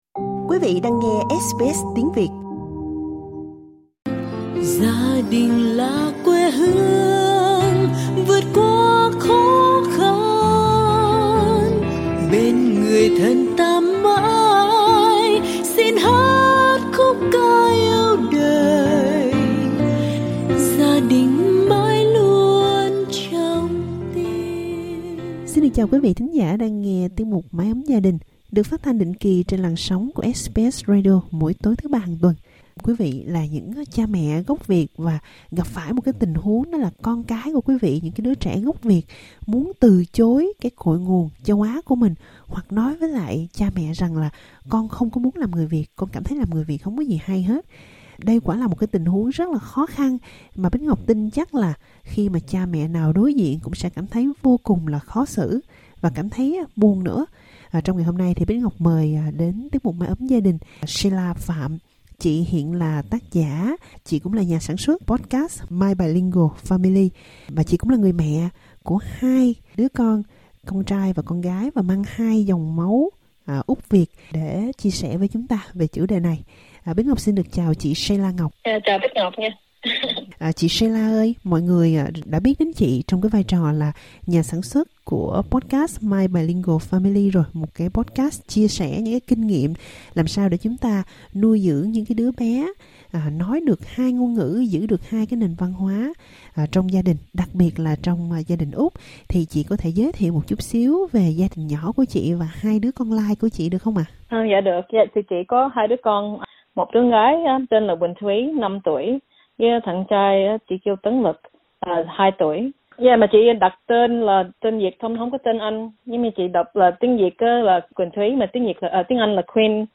Mời nhấn vào audio để nghe trò chuyện